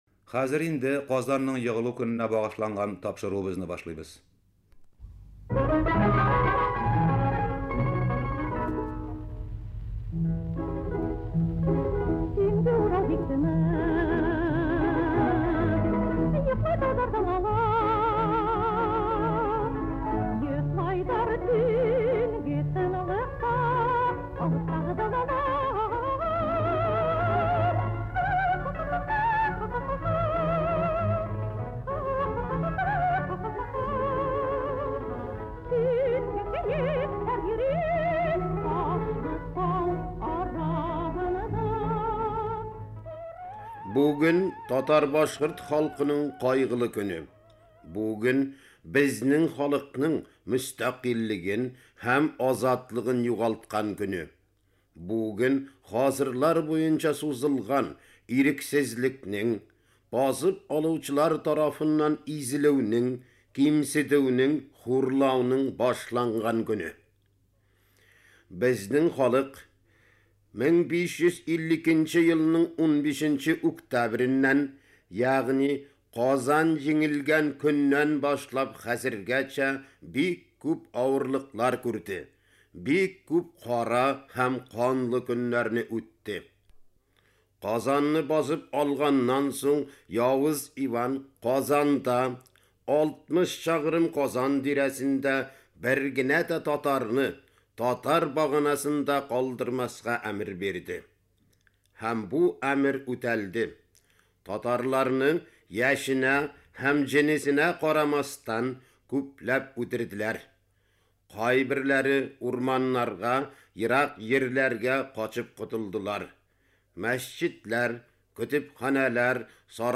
Кыска язмада диктор Казан ханлыгының яулап алынуы һәм аның нәтиҗәләре турында сөйли. Шул ук вакытта ул татар халкының хәзергәчә үзен милләт буларак саклап килүен, ата-бабаларның милләтне авыр чакта да саклап калуын искәртә.